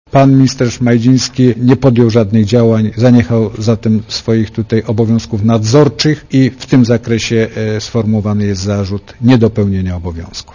Posłuchaj posła Wassermanna dla Radia Zet